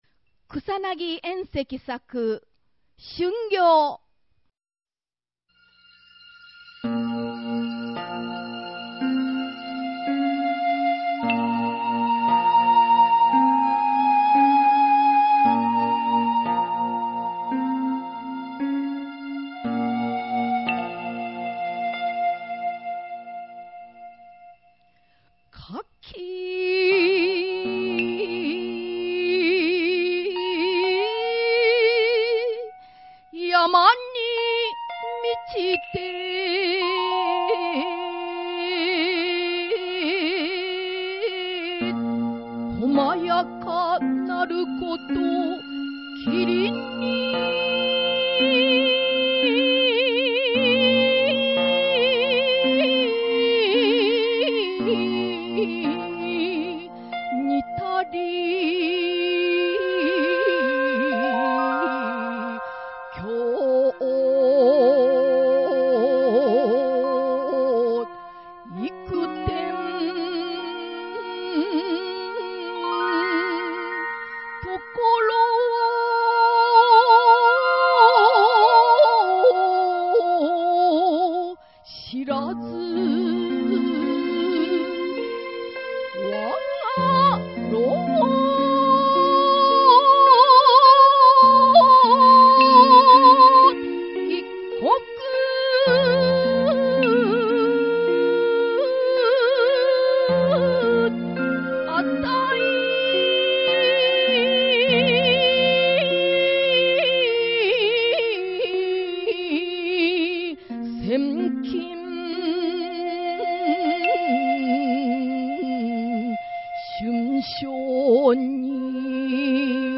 吟者